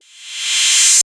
SOUTHSIDE_percussion_cymbrevvy.wav